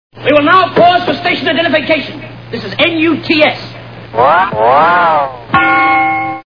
Three Stooges Movie Sound Bites
Sfx: Train and Clang.